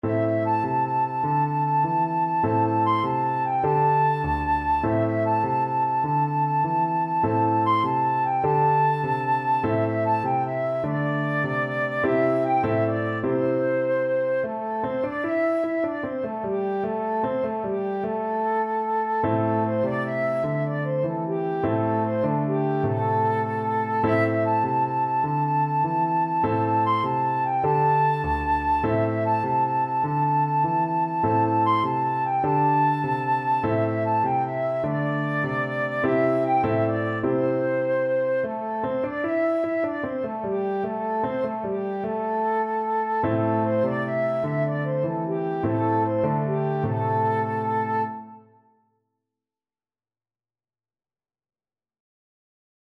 Flute
12/8 (View more 12/8 Music)
A minor (Sounding Pitch) (View more A minor Music for Flute )
Moderato .=100
Traditional (View more Traditional Flute Music)
Indian